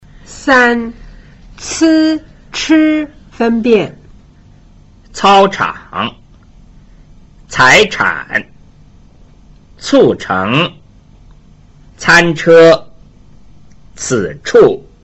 1. 平舌音 z c s和 捲舌音 zh ch sh 的比較﹕
3） c – ch分辨